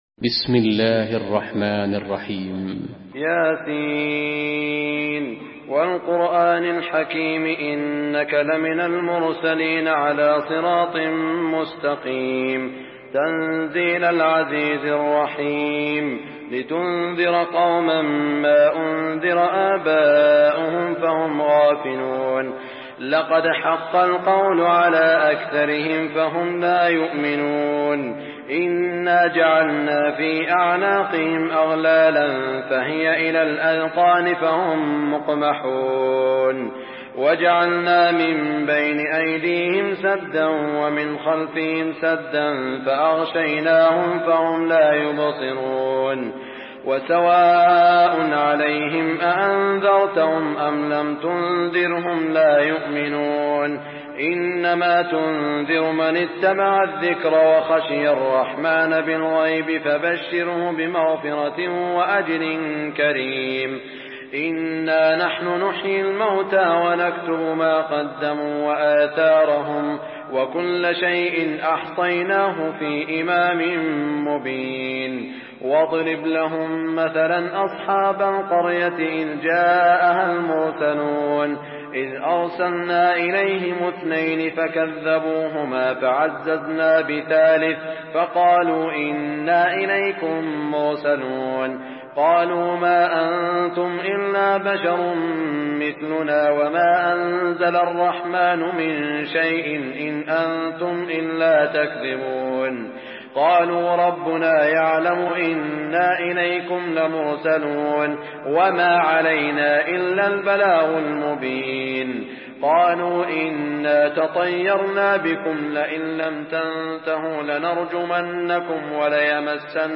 Surah Yasin MP3 by Saud Al Shuraim in Hafs An Asim narration.
Murattal Hafs An Asim